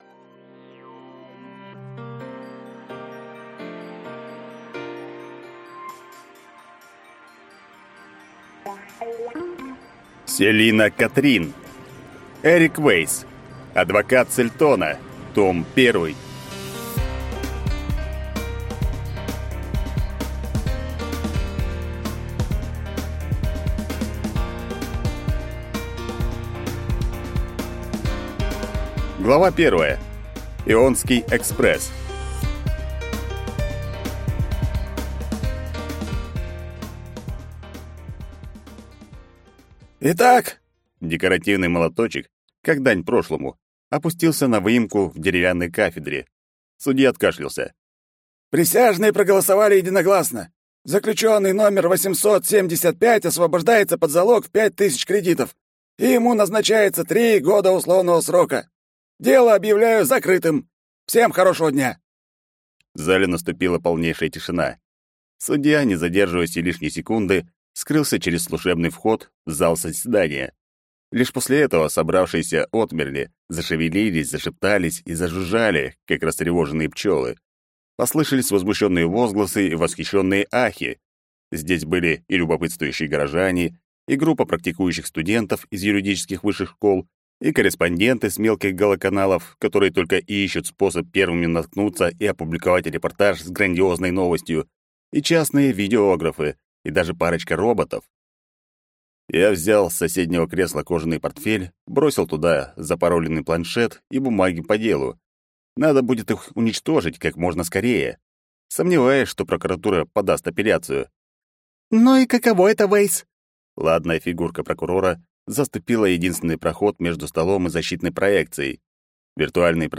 Аудиокнига Эрик Вейсс. Адвокат с Эльтона | Библиотека аудиокниг